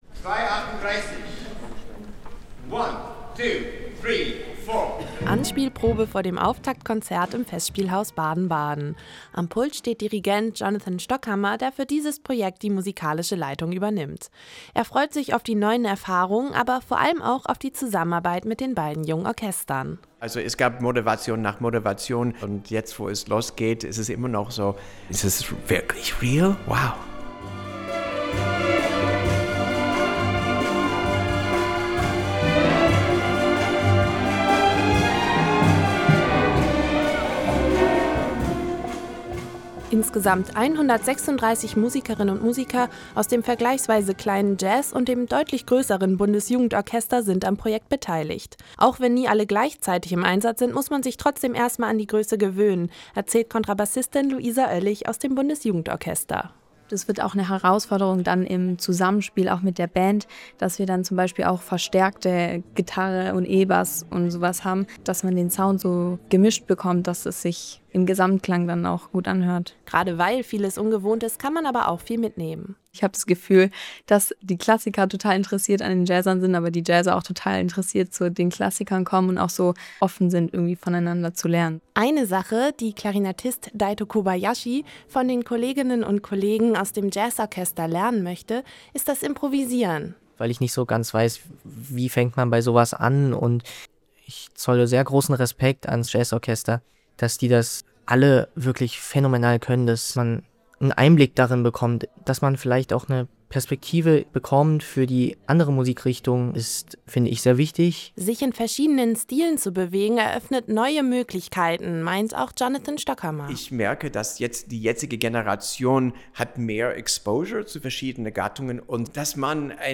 Probenbesuch